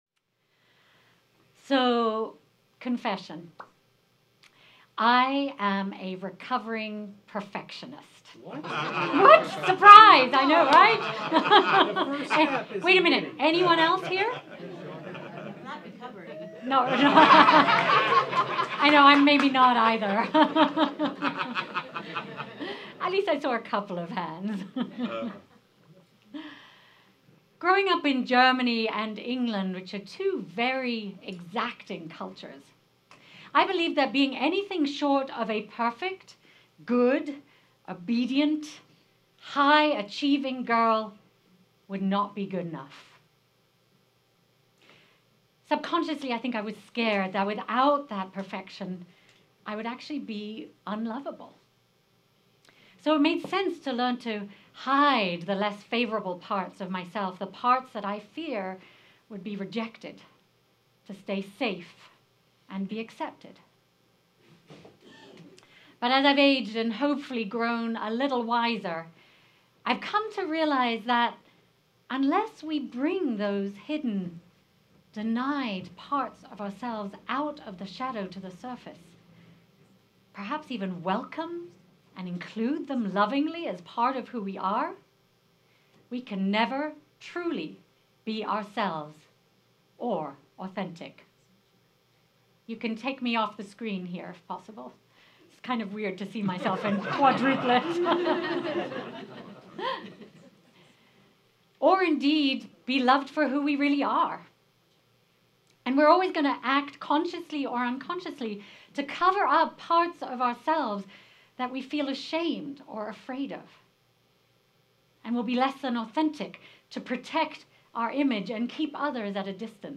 This service invites us to explore what it means to embrace the shadowed parts of ourselves—the fears, flaws, and complexities we often hide. Through love and self-compassion, we’ll discover how honoring our whole selves deepens our capacity to accept and include others, creating a more authentic and connected community.